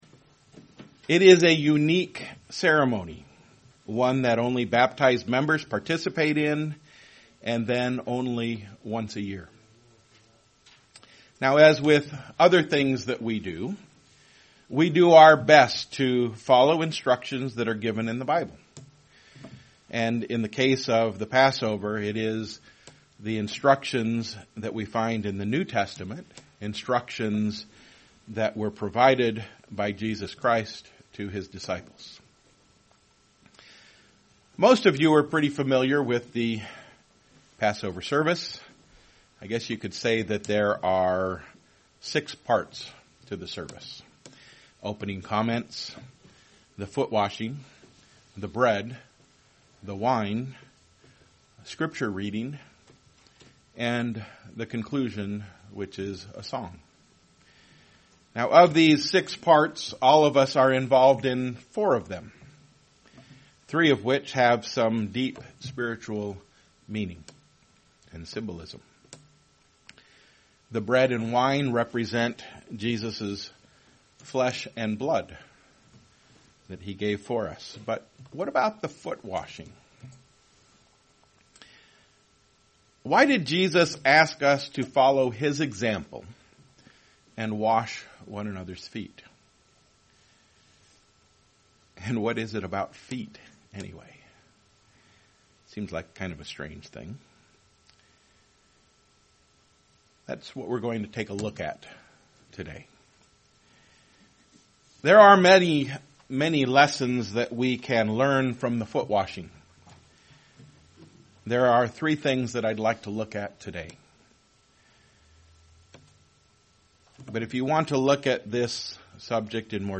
Given in Freeland, MI